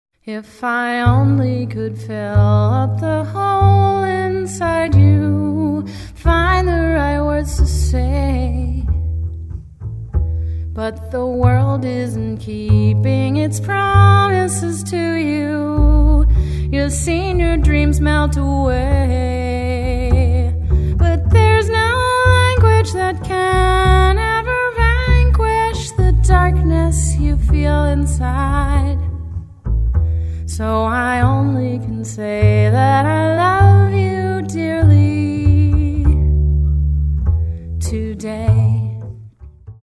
lead vocal, acoustic guitar